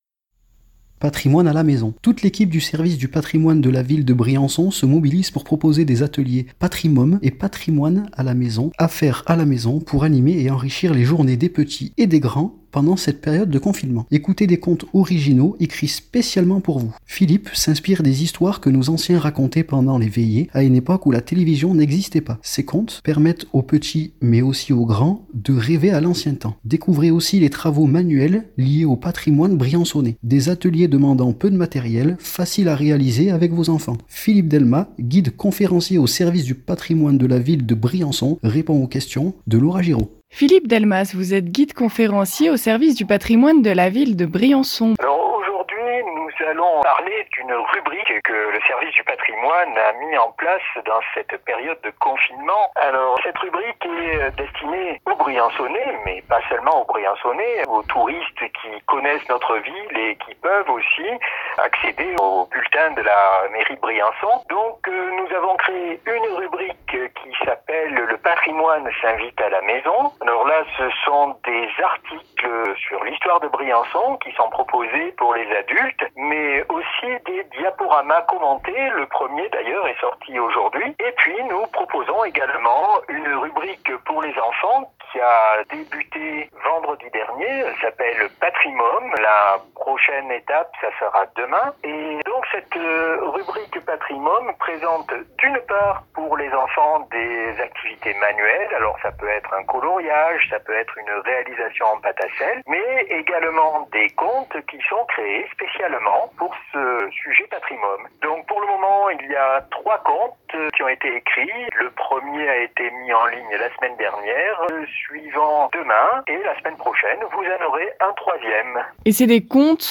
guide conférencier